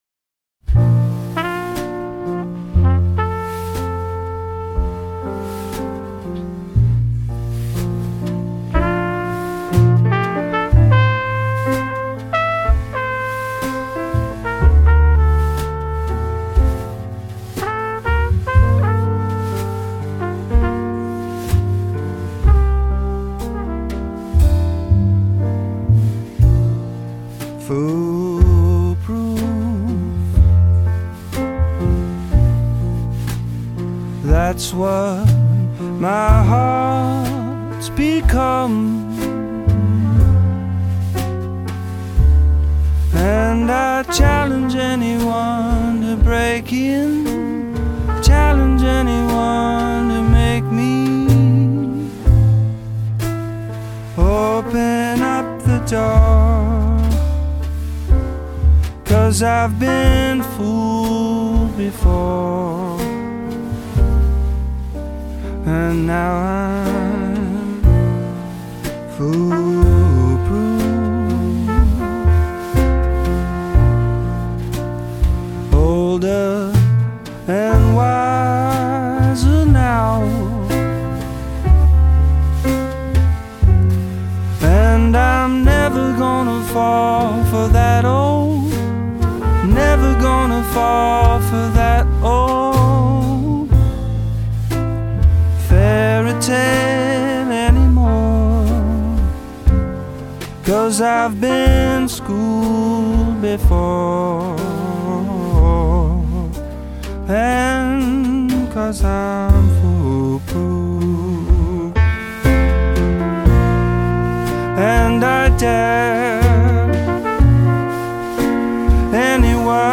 jazzy torch ballad
smokey piano bar ballads